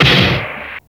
STARTLE SNR.wav